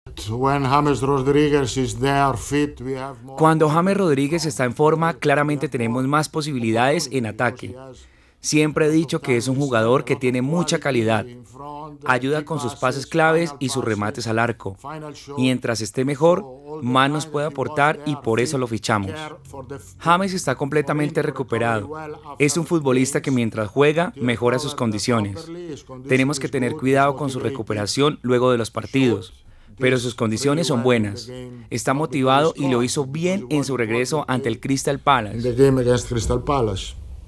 (Carlo Ancelotti, DT del Everton)
"Cuando James Rodríguez está en forma claramente tenemos más posibilidades en ataque, siempre he dicho que es un jugador que tiene mucha calidad. Ayuda con sus pases claves y sus remates al arco. Mientras esté mejor, más nos puede aportar y por eso lo fichamos", sostuvo Ancelotti en la rueda de prensa previa al partido frente al Brigthon.